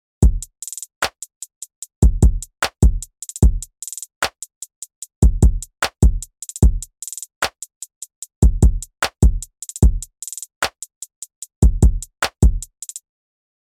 実際のドラムパターンでノートリピートを使ってみた例